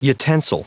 Prononciation du mot utensil en anglais (fichier audio)
utensil.wav